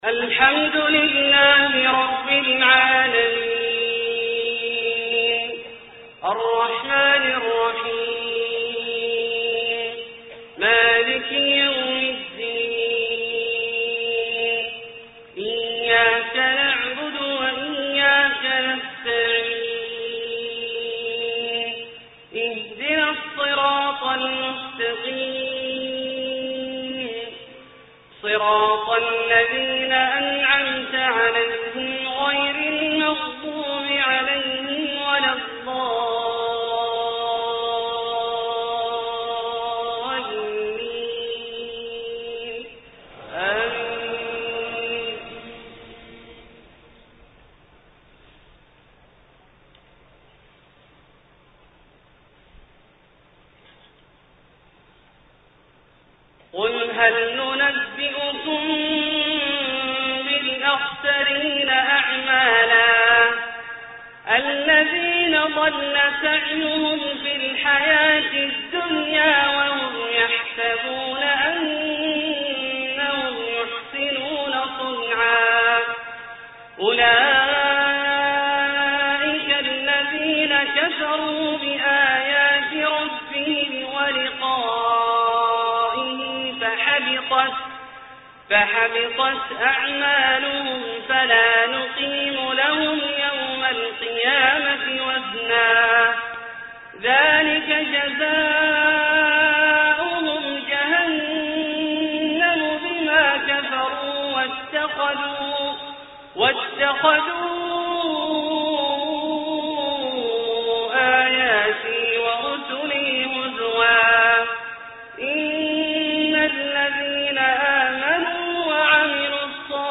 صلاة المغرب 5-3-1429 من سورتي الكهف{103-110} و الصف{10-14} > ١٤٢٩ هـ > الفروض - تلاوات عبدالله الجهني